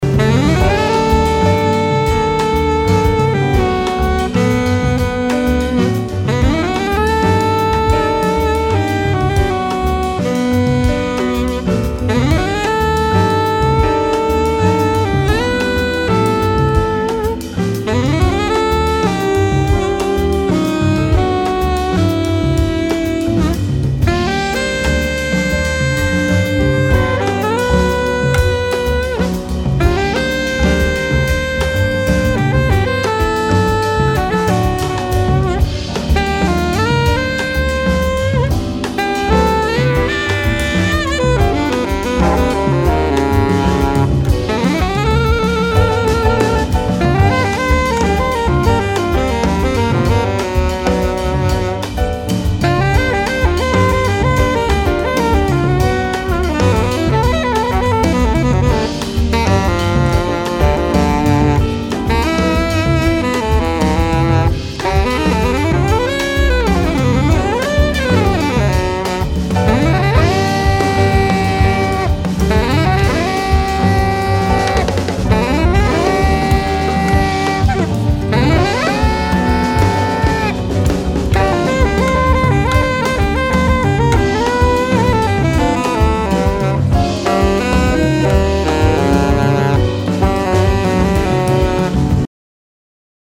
alto and sopranino saxophones
piano and keyboards
double bass
drums